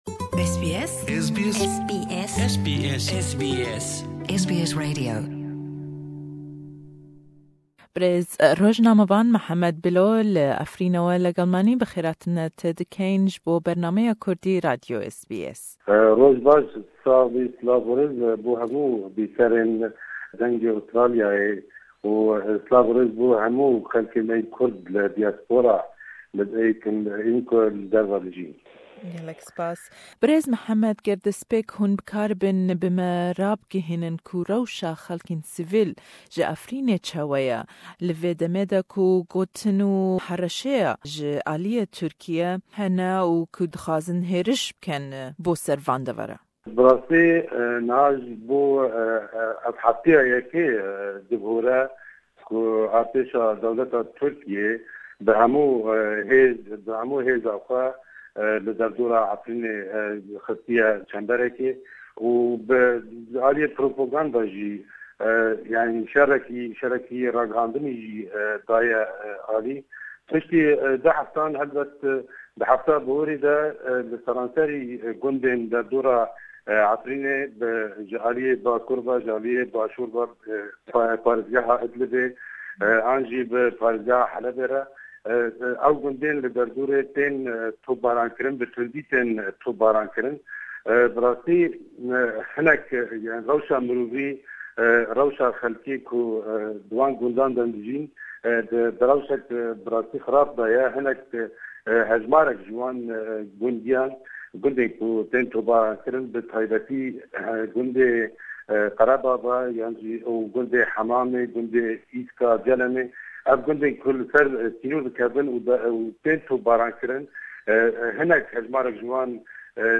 SBS Kurdish